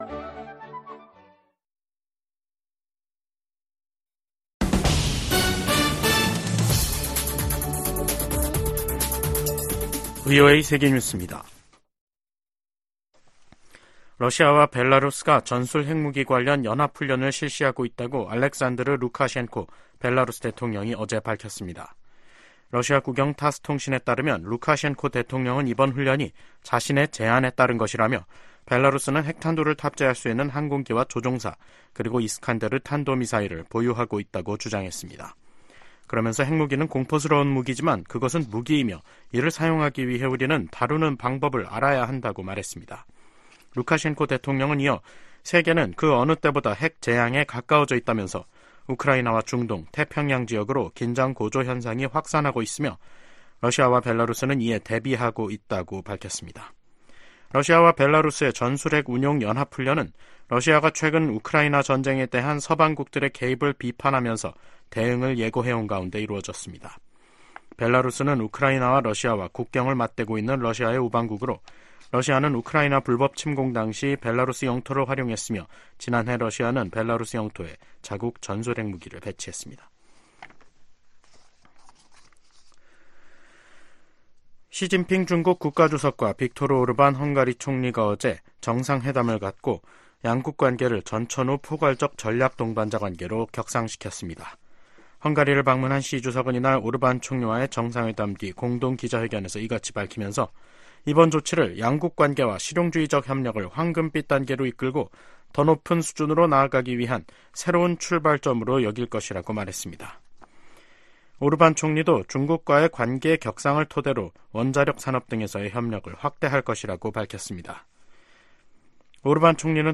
VOA 한국어 간판 뉴스 프로그램 '뉴스 투데이', 2024년 5월 10일 2부 방송입니다. 북한 가상화폐 계좌에 대한 몰수 소송을 담당한 워싱턴 DC 연방법원이 해당 계좌 270여개에 대한 몰수를 명령했습니다. 중국이 최근 탈북민 60여 명을 강제 북송한 것으로 알려진 가운데 미 국무부 북한인권특사가 이에 대한 심각한 우려를 표시했습니다.